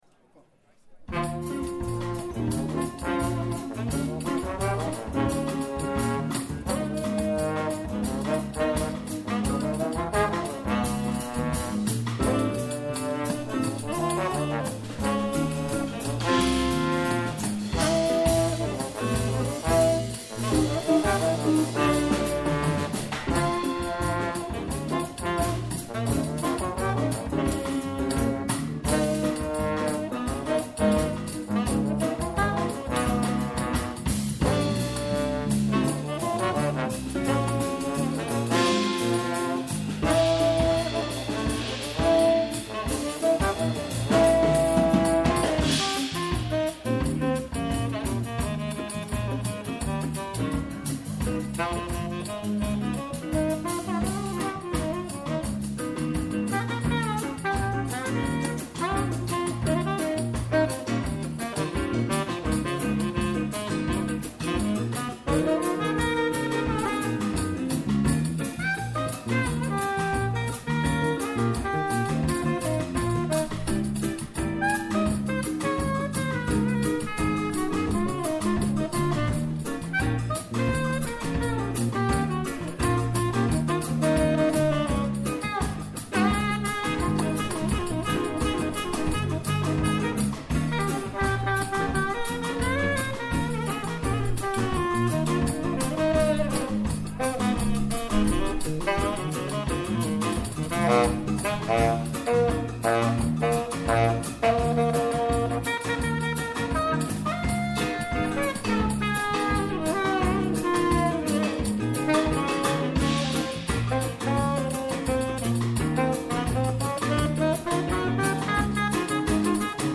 live in der Sandgrube 25 in Speikern am 16.7.2022
saxophone
trombone
guitar
bass
drums